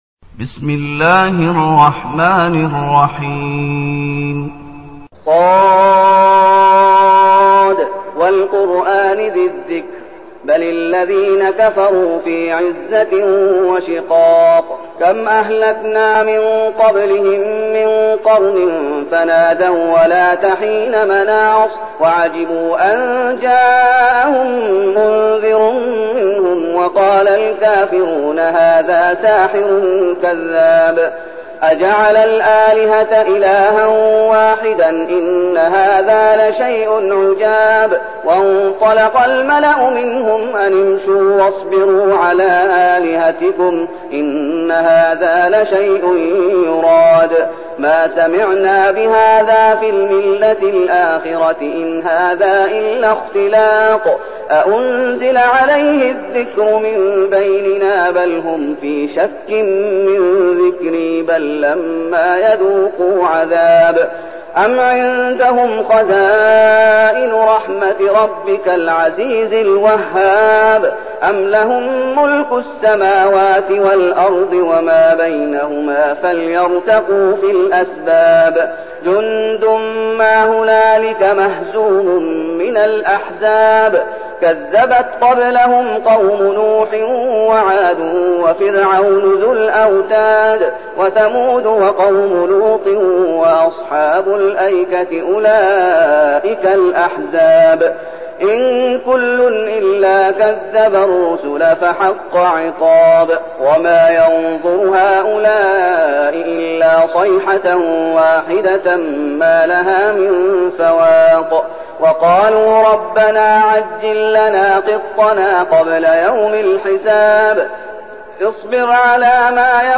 المكان: المسجد النبوي الشيخ: فضيلة الشيخ محمد أيوب فضيلة الشيخ محمد أيوب ص